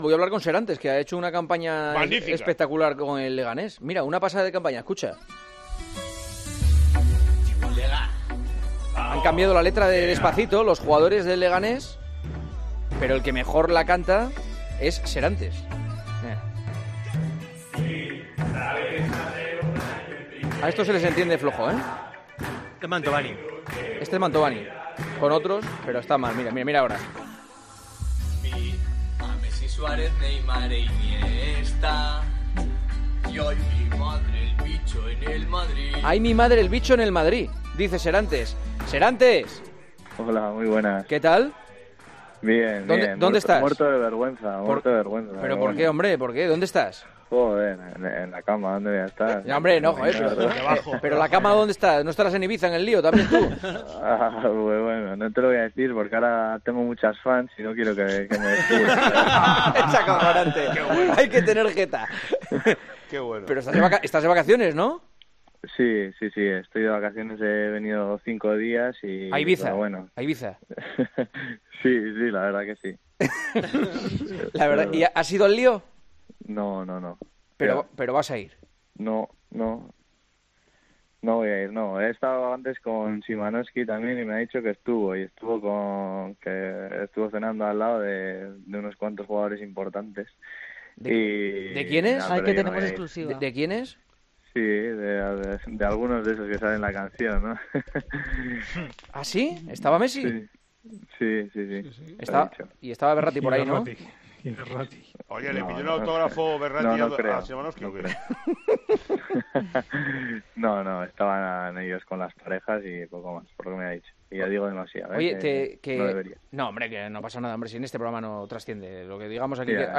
Jon Ander Serantes se atreve a cantar en El Partidazo de COPE el último 'spot' del CD Leganés
Con el humor habitual y, esta vez, basado en el popular 'Despacito', el CD Leganés ha creado un nuevo anuncio que protagoniza, entre otros, Serantes. El portero se atrevió a reproducirlo con Juanma Castaño.